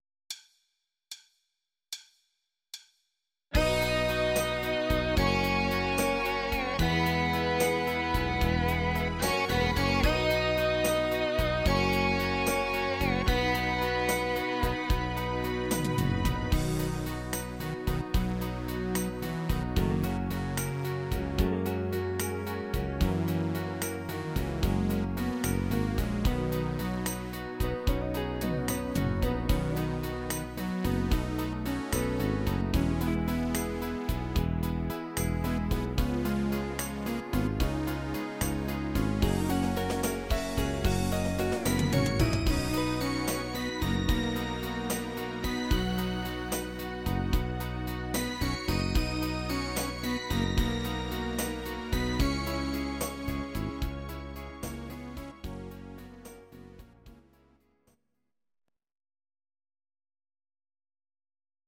Audio Recordings based on Midi-files
Pop, Dutch, 1970s